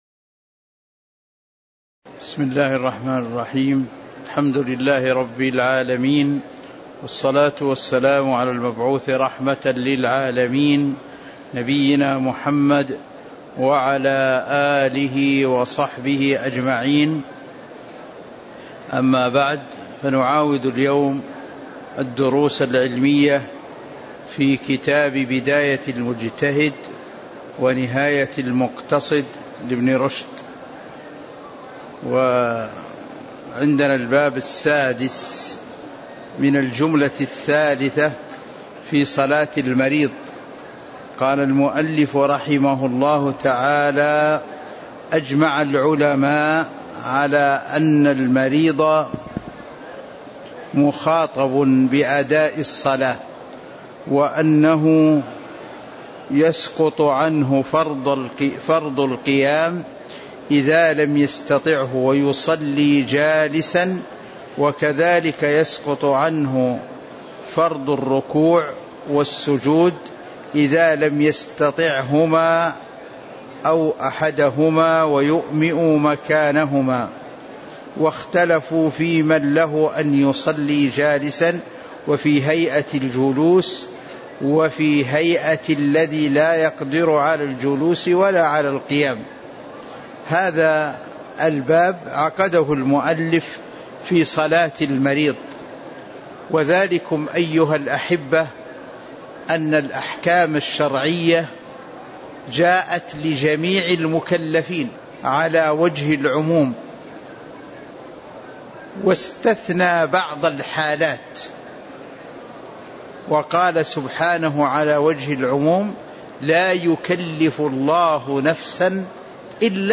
تاريخ النشر ١ صفر ١٤٤٤ هـ المكان: المسجد النبوي الشيخ